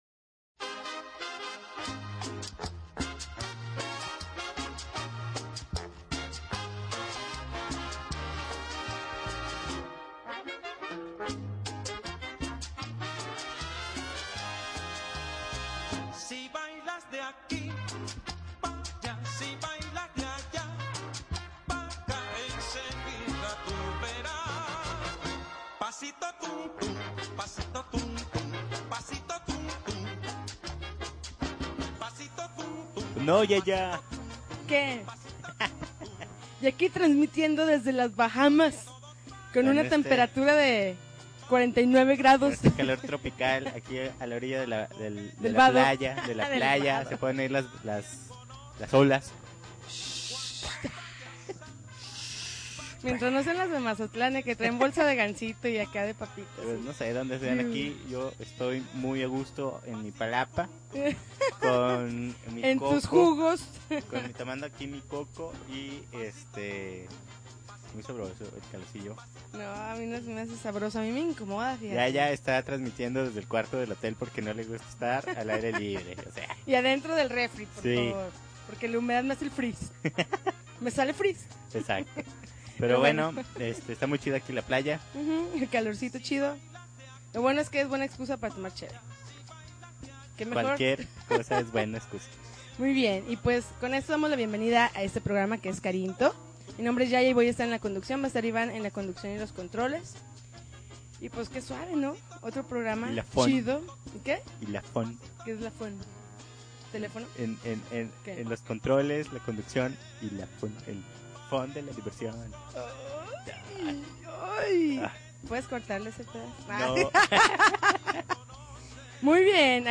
May 30, 2011Podcast, Punk Rock Alternativo